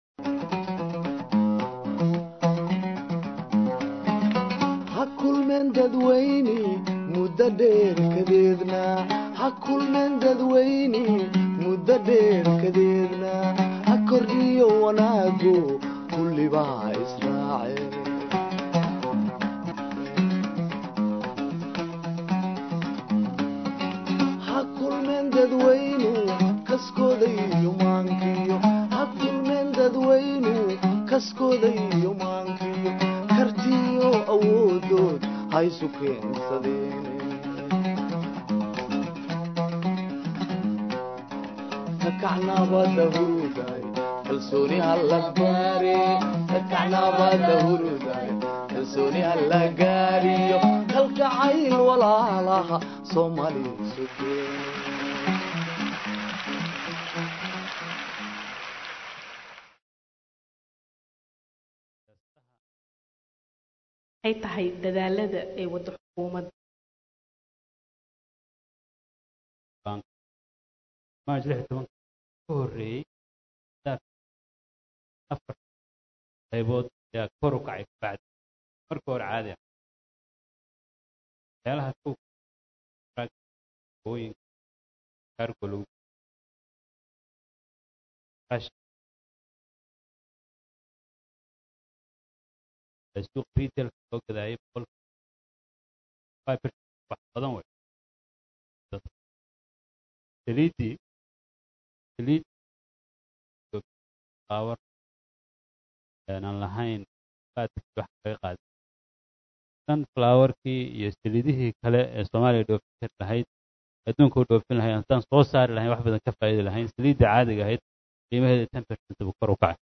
Dhageyso: Dood ku saabsan la xisaabtanka Xukuumadda ee wax ka qabashada Covid-19